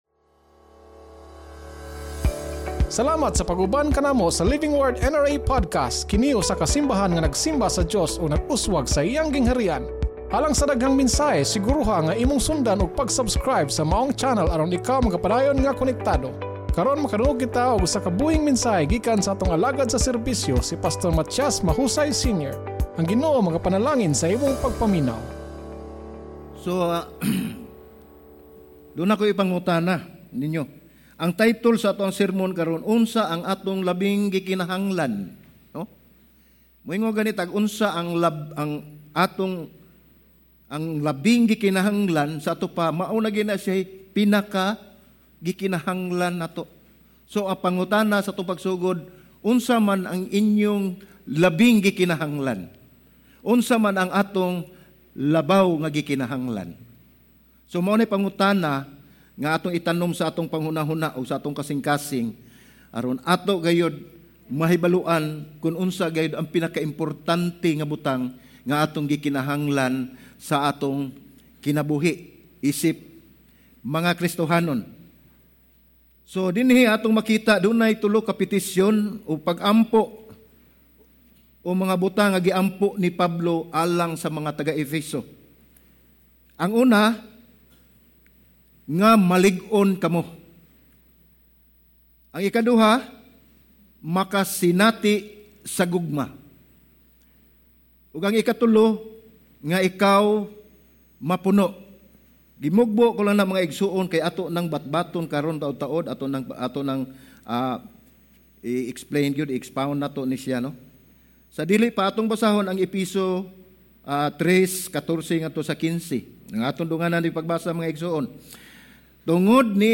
Sermon Title: UNSA ANG ATONG LABAW NGA GIKINAHANGLAN Scripture Text: EFESO 3:14-21 (ANG BIBLIA)